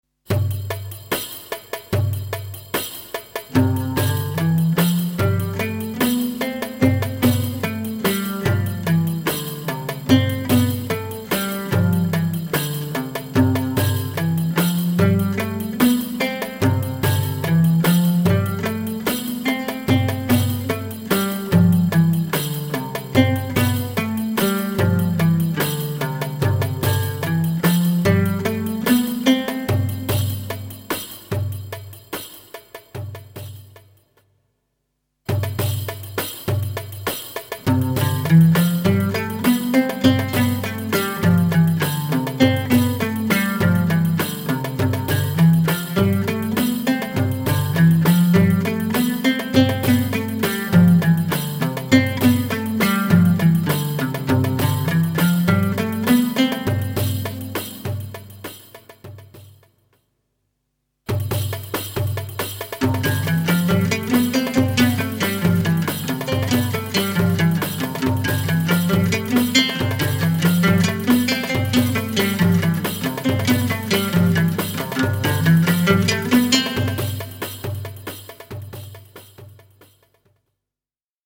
Découvrez une méthode unique pour apprendre le Oud